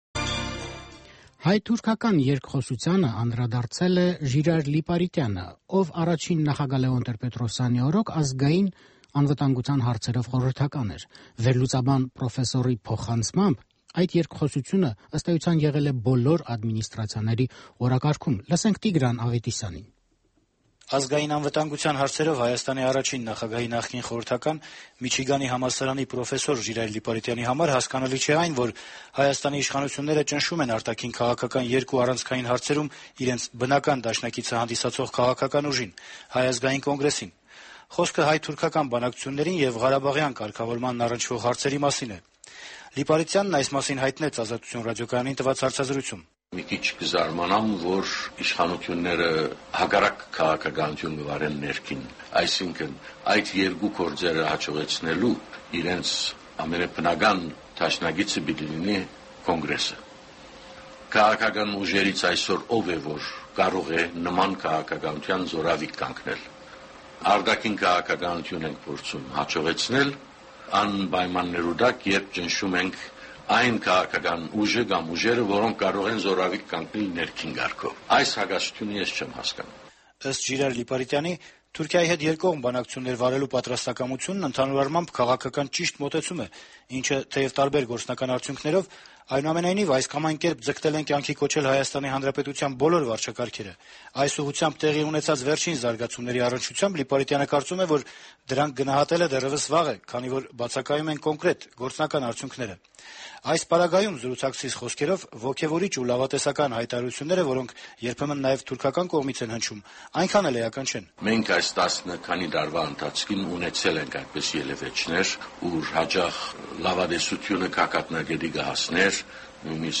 Ժիրայր Լիպարիտյանի հարցազրույցը